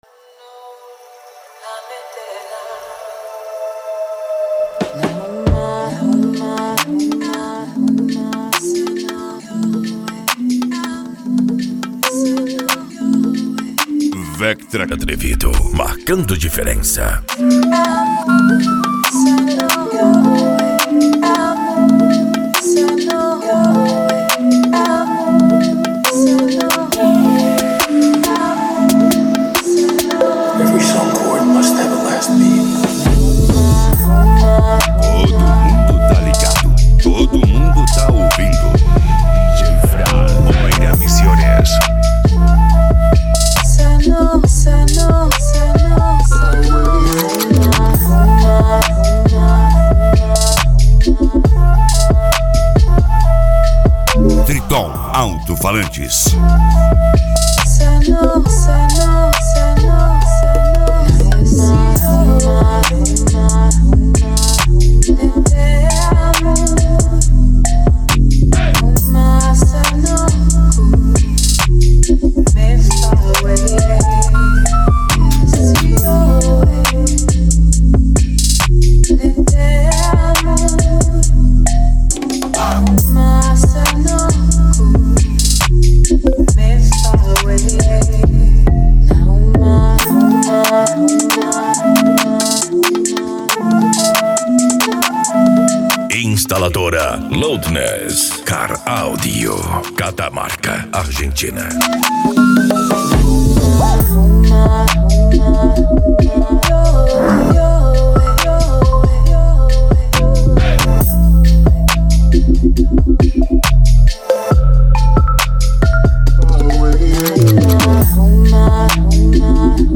Bass
Cumbia
Musica Electronica
Hip Hop
Remix